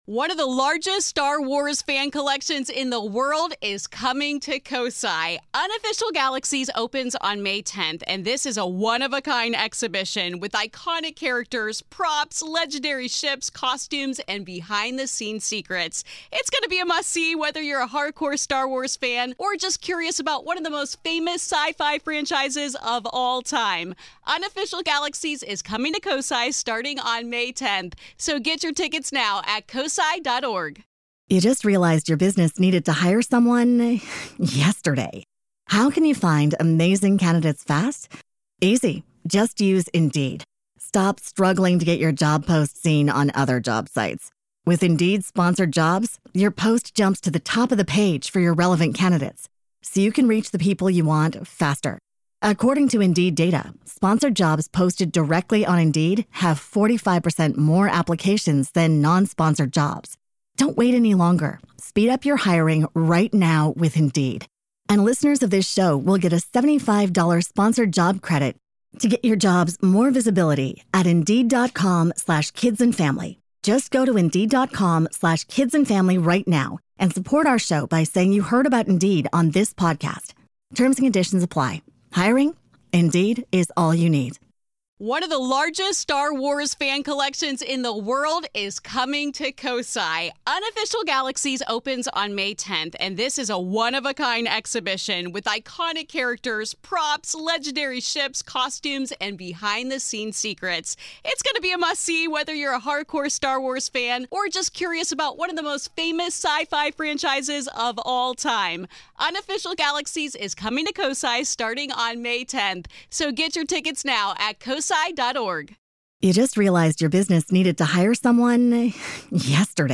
a conversation with author